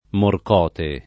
[ mork 0 te ]